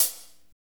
HAT F S C05R.wav